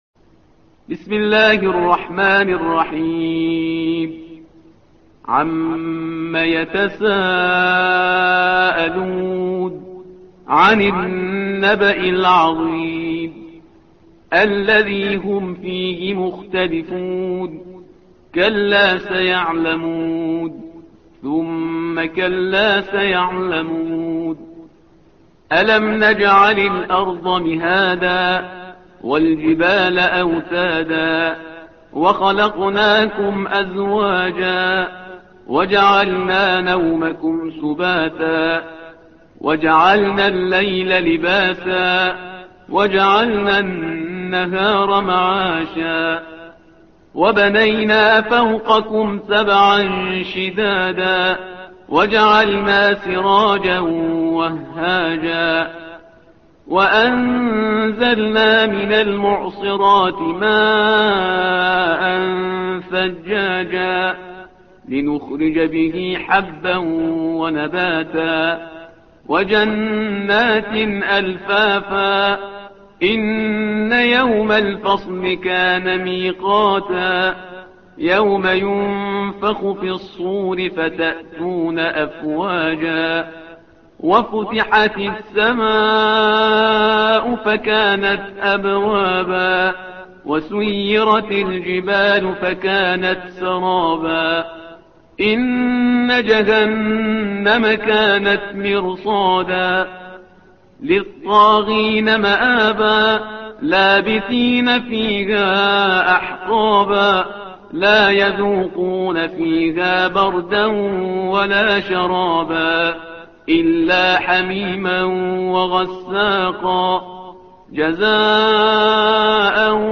الصفحة رقم 582 / القارئ